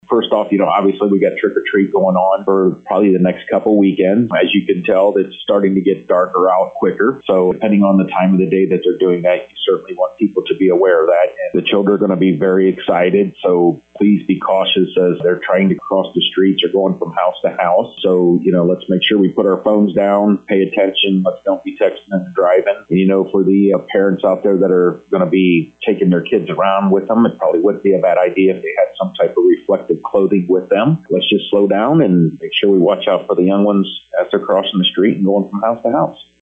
To hear Sheriff Timmerman: